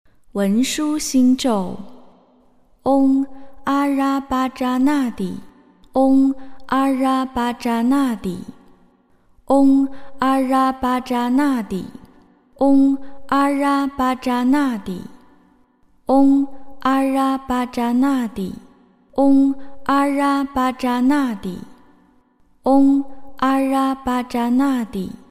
诵经
佛音 诵经 佛教音乐 返回列表 上一篇： 金刚经-离相寂灭分第十四 下一篇： 佛顶尊胜陀罗尼经-1 相关文章 貧僧有話22說：我一直生活在“众”中--释星云 貧僧有話22說：我一直生活在“众”中--释星云...